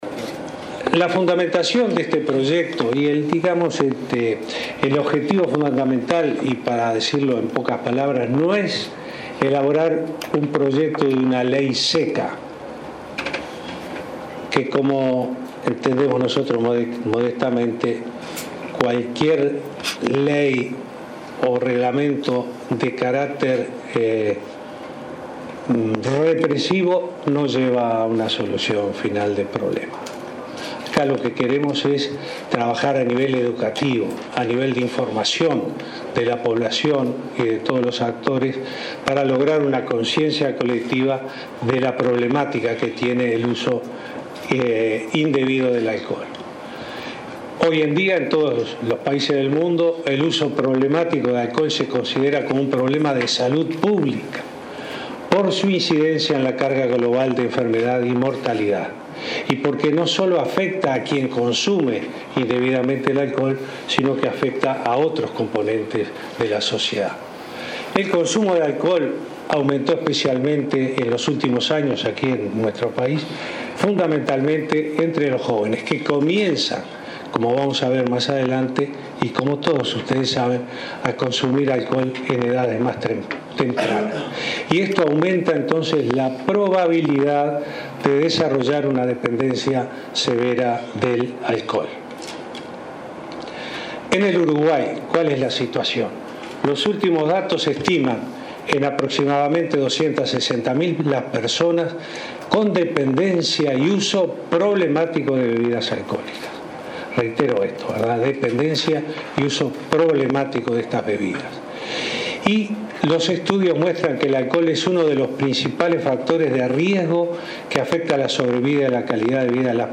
El presidente Vázquez presentó el proyecto de ley para regular el consumo de alcohol, acto al que asistieron legisladores de todos los partidos. Respecto a la incidencia en la población uruguaya, dijo que hay 260.000 personas con uso problemático de alcohol, y que siete de cada diez jóvenes que tomaron alcohol en los últimos 15 días sufrieron un episodio de intoxicación.